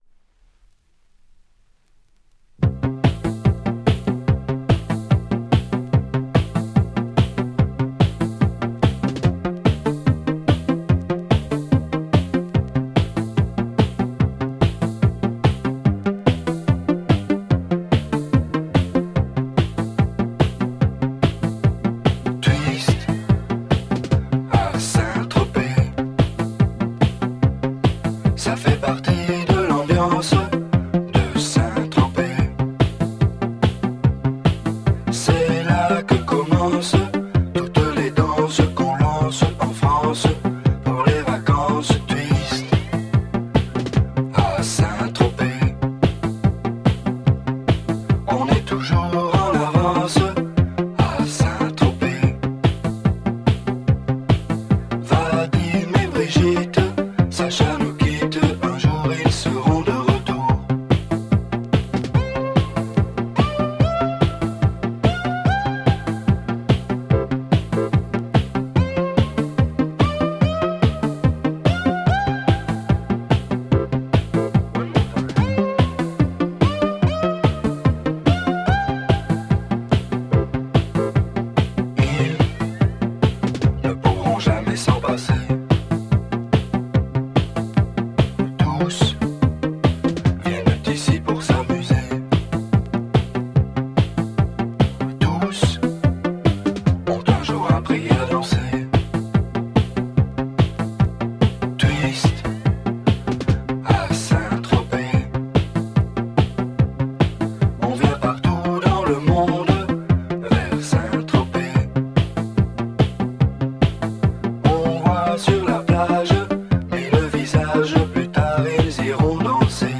Belgium minimal electro French press